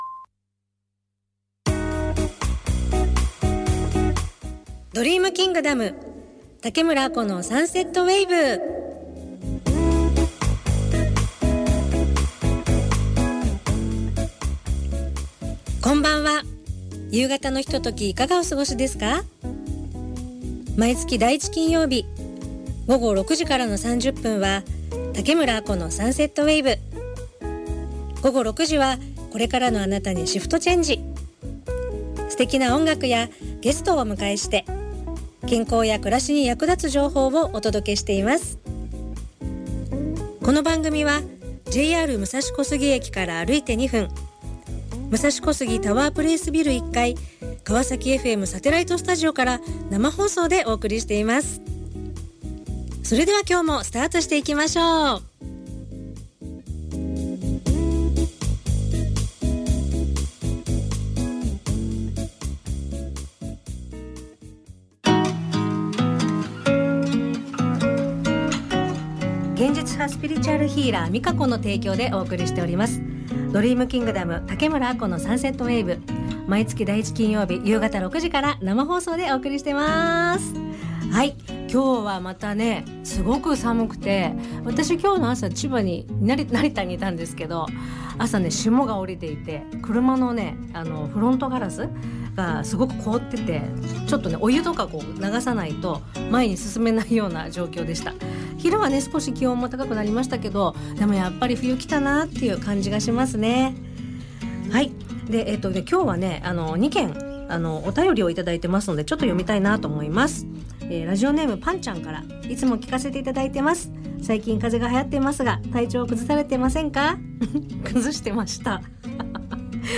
＊かわさきFMサテライトスタジオから生放送